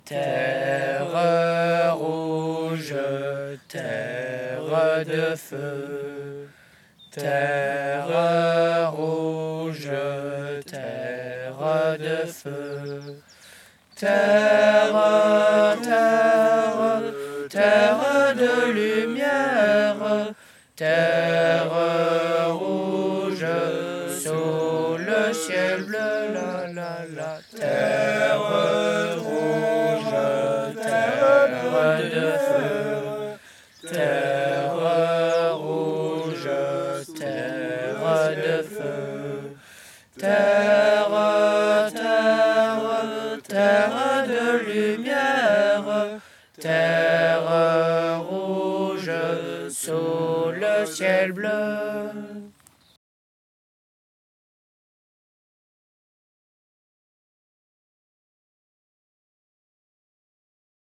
Genre : chant
Type : chant de mouvement de jeunesse
Interprète(s) : Les Scouts d'Europe d'Arlon
Lieu d'enregistrement : Arlon
Enregistrement réalisé dans le cadre de l'enquête Les mouvements de jeunesse en chansons.